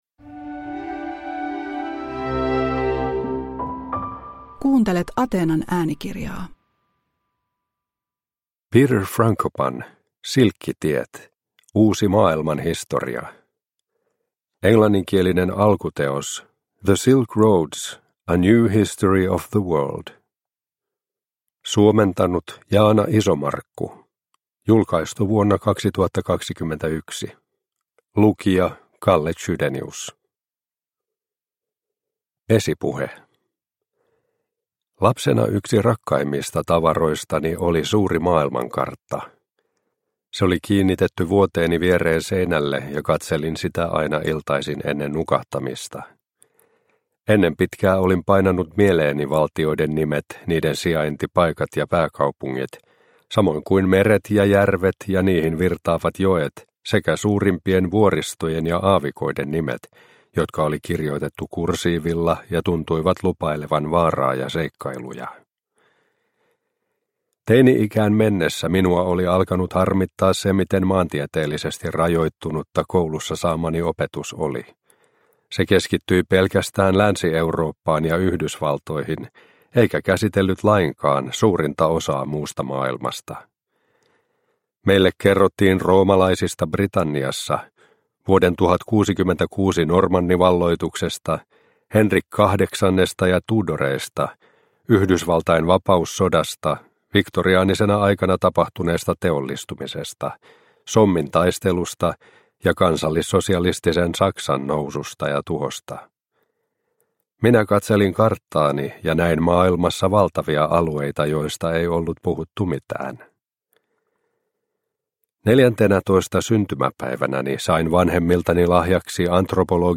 Silkkitiet – Ljudbok – Laddas ner